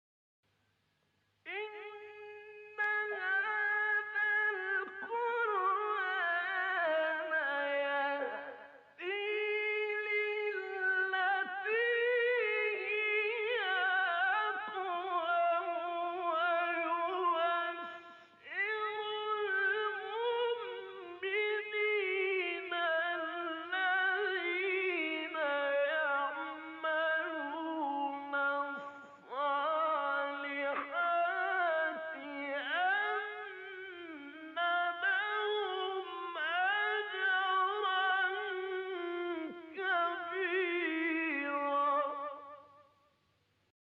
گروه شبکه اجتماعی: مقاطعی از قاریان مصری که در مقام رست اجرا شده است، می‌شنوید.
مقام رست